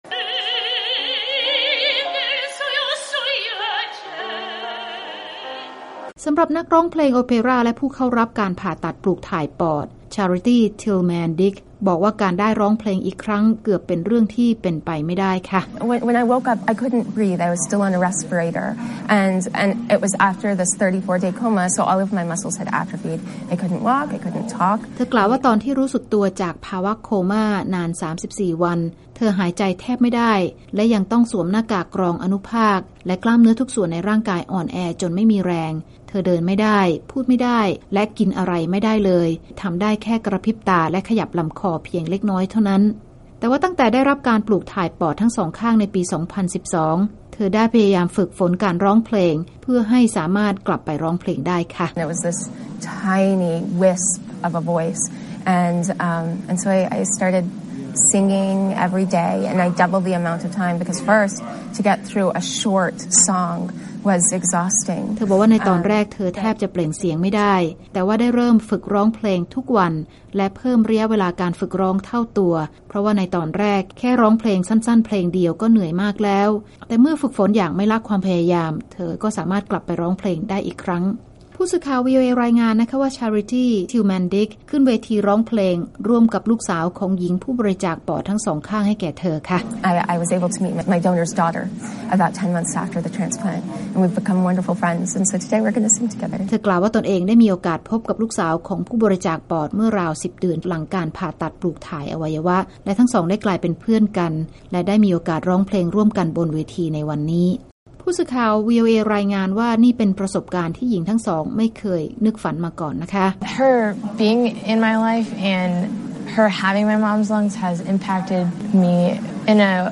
หญิงทั้งสองคนได้ร้องเพลง "American Rainbow" ร่วมกันเป็นครั้งเเรกบนเวทีในงานประชุม Medical Innovation Summit ประจำปี ต่อหน้าบรรดาแพทย์และผู้เข้าร่วมงานหลายร้อยคนเมื่อเร็วๆ นี้